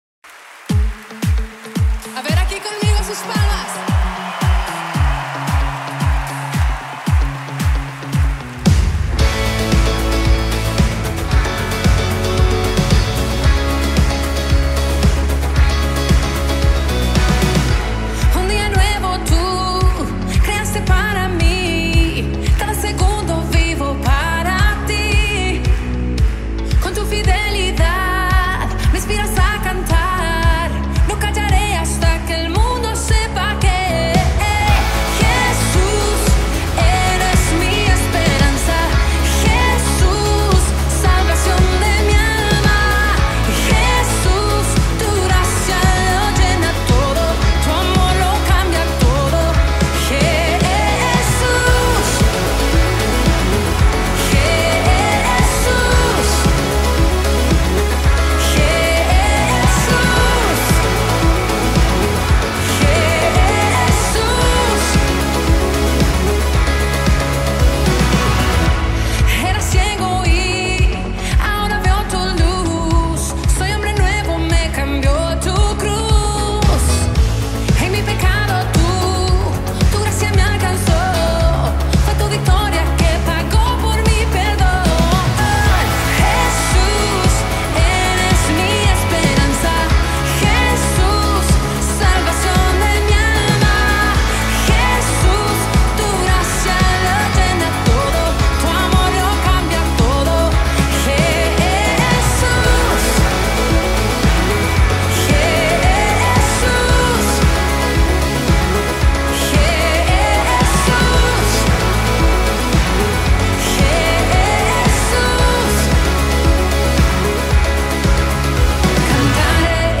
436 просмотров 264 прослушивания 13 скачиваний BPM: 113